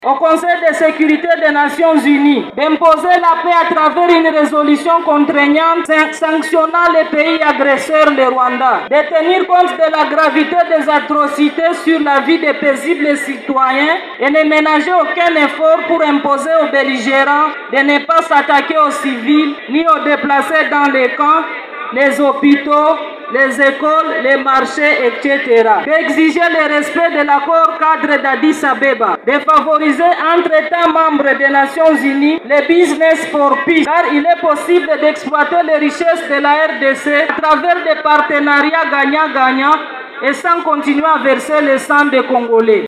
Les forces vives du Sud-Kivu dénoncent également les prises de positions parfois ambiguës des pays comme la France, des Etats unies d’Amérique et la grande bretagne en rapport avec la guerre actuelle. Un extrait lu ici
ELEMENT-MANIFESTATION-SOCIV-BUKAVU-FR-1.mp3